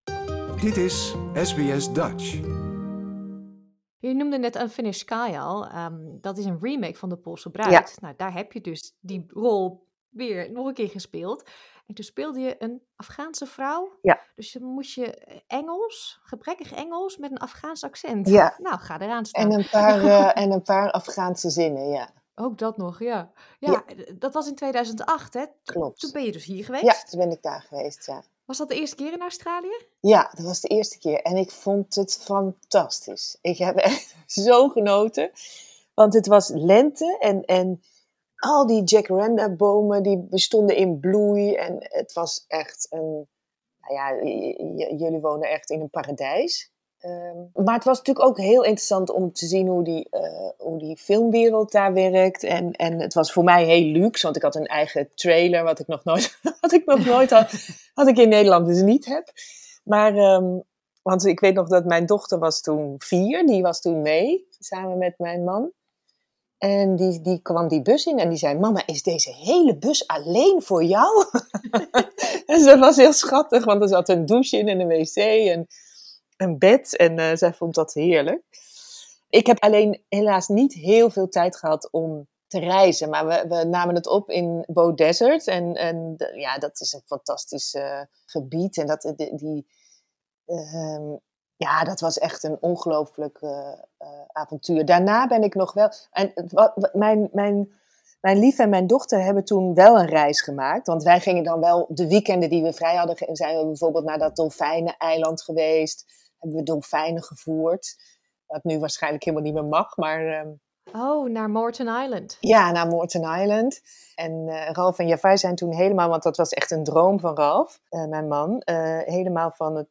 Deel 2 van het interview met actrice Monic Hendrickx.